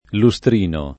lustrino [ lu S tr & no ] s. m.